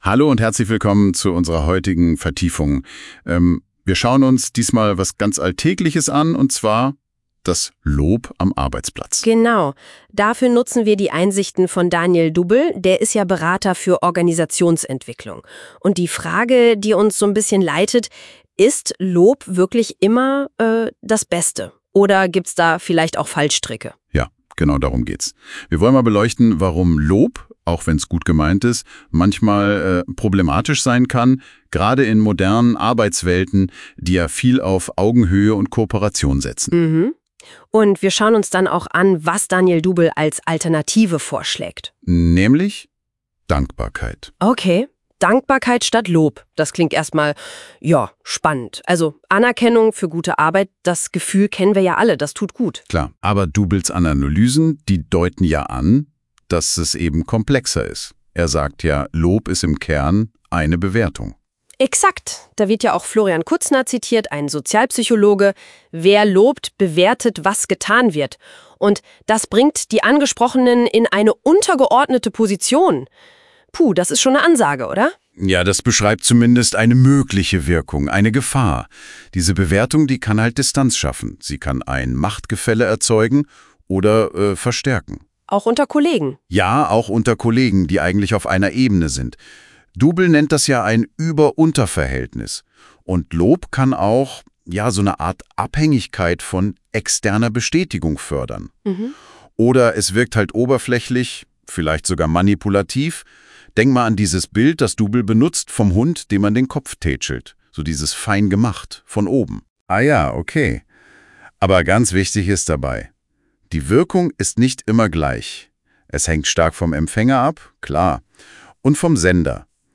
Hier bekommst du einen durch NotebookLM generierten KI-Podcast Dialog zum Thema Lob, Wertschätzung und Anerkennung.